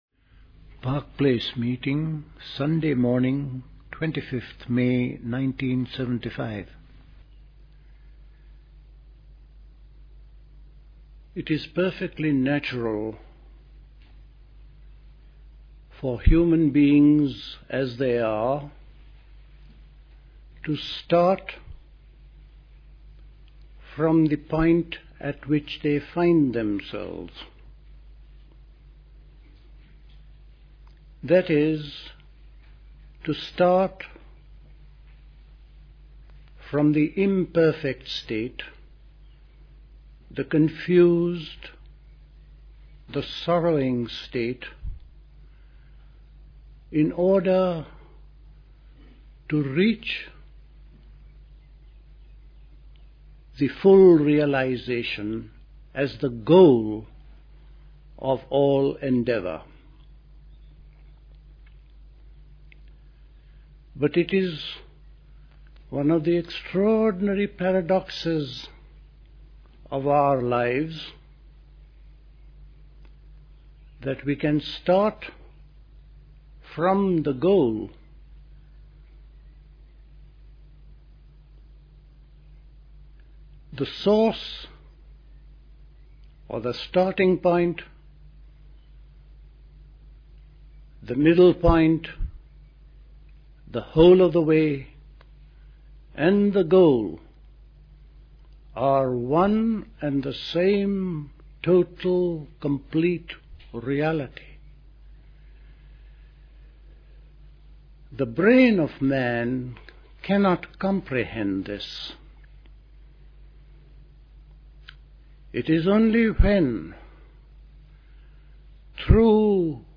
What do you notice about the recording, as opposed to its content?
Recorded at the 1975 Park Place Summer School.